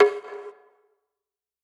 Percs
Houstalanta Drum MDB.wav